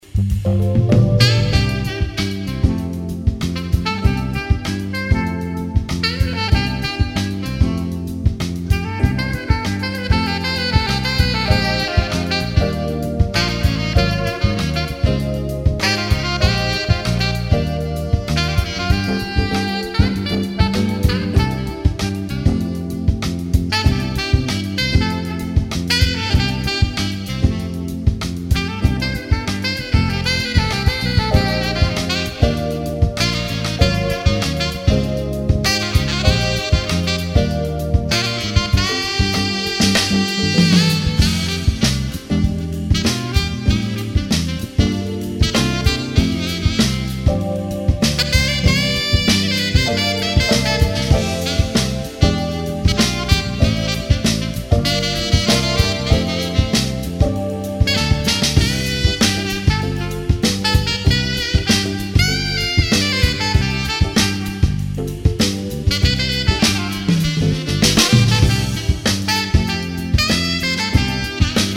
Las Vegas Saxophone Player for Hire, Sax Soloist
Jazz / Smooth Jazz